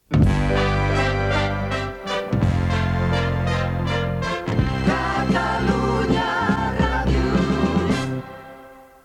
Indicatiu llarg